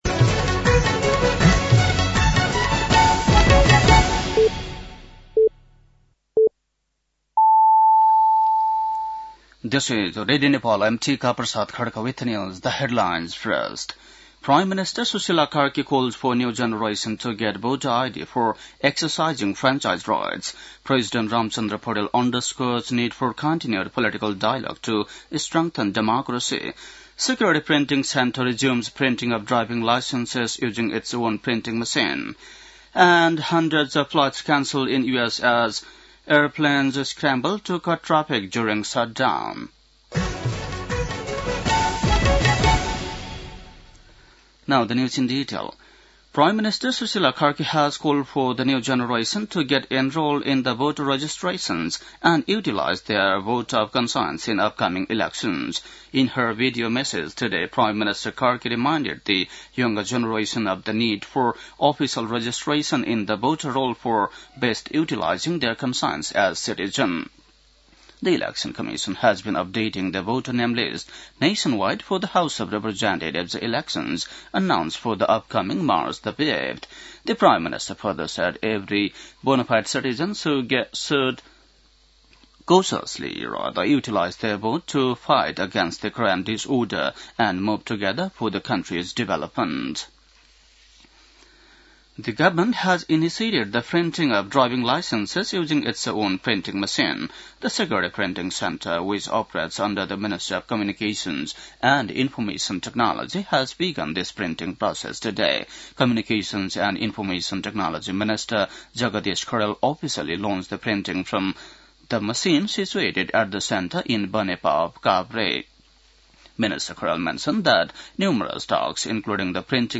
बेलुकी ८ बजेको अङ्ग्रेजी समाचार : २१ कार्तिक , २०८२
8-pm_english-news.mp3